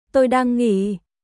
Tôi đang nghỉ.休憩中ですトイ ダン ンギー